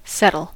settle: Wikimedia Commons US English Pronunciations
En-us-settle.WAV